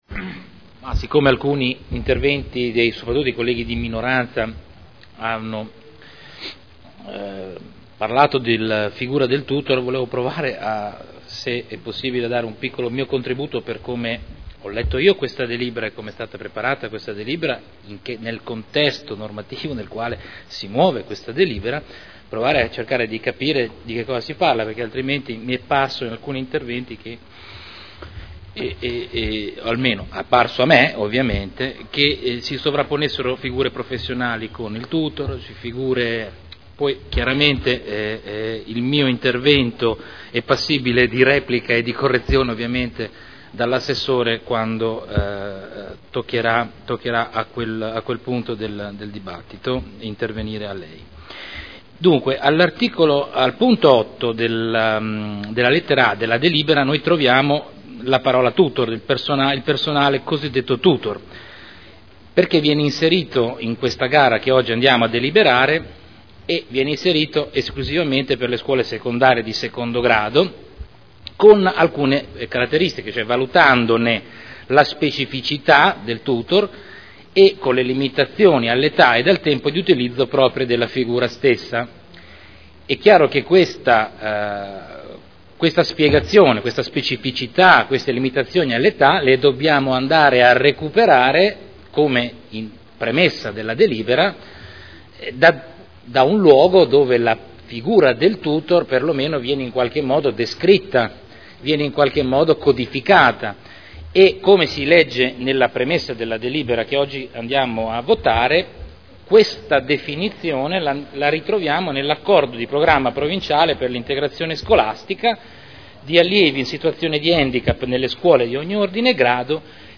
Dibattito. Linee di indirizzo per l’affidamento dei servizi di appoggio educativo assistenziale per gli alunni diversamente abili delle scuole di ogni ordine e grado site nel Comune di Modena (Commissione consiliare del 15 marzo e 28 marzo 2012)